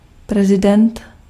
Ääntäminen
France: IPA: /pʁe.zi.dɑ̃/